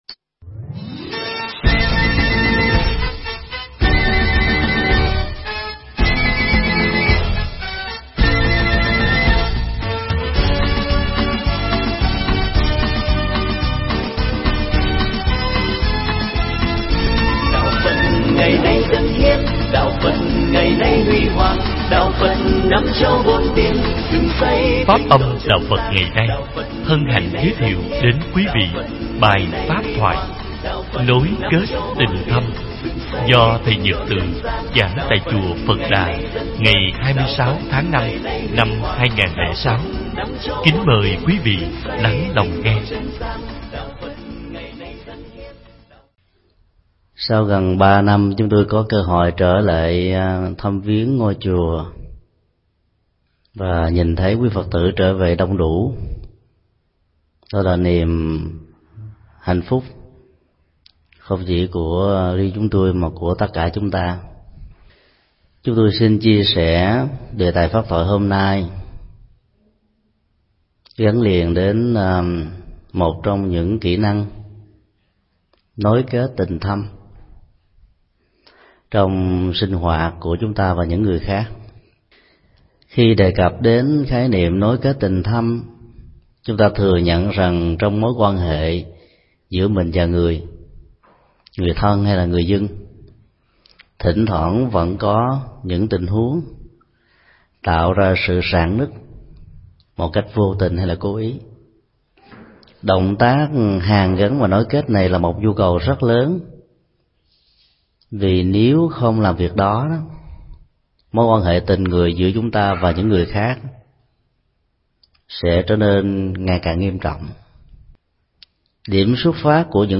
Bài pháp thoại
giảng tại chùa Phật Đà - Autralia